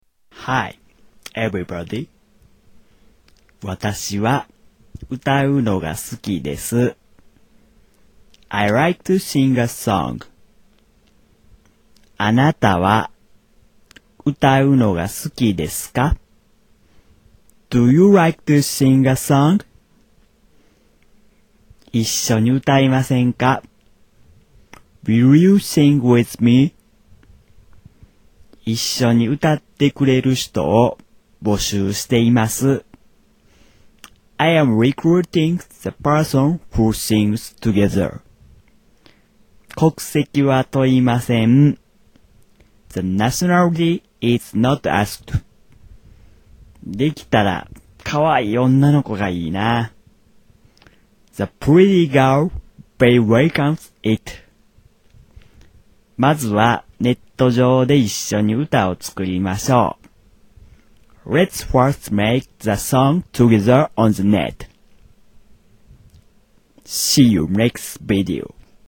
Tags: Travel Japan Japanese Accent Japanese accent